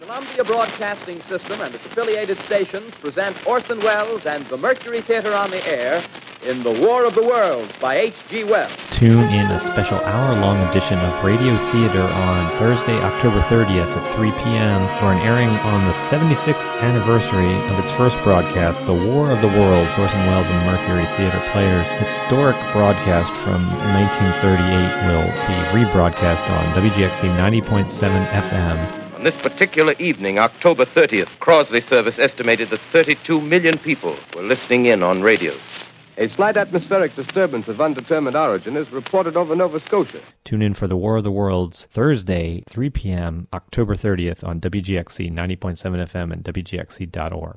An Official promo for "The War of the Worlds" broadcast on "Radio Theatre" Oct. 30. (Audio)